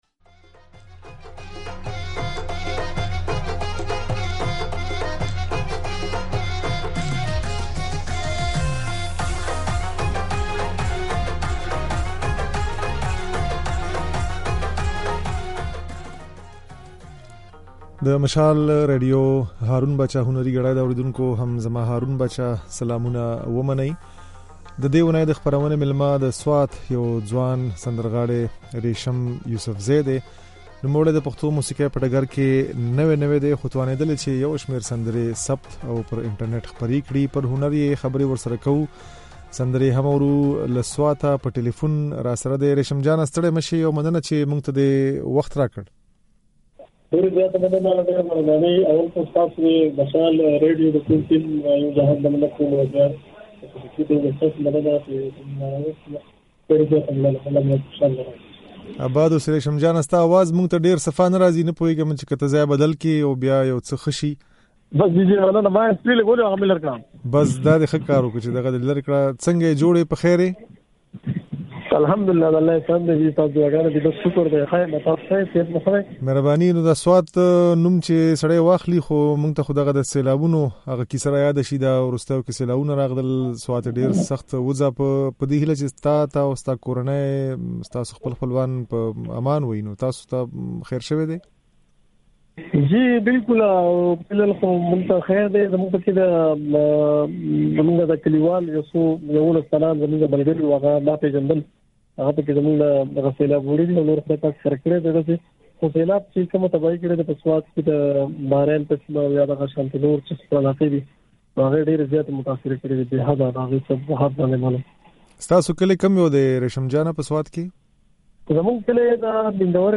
دا خبرې او ځينې سندرې يې د غږ په ځای کې اورېدای شئ.